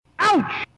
ouch-rock-n-roll-racing.mp3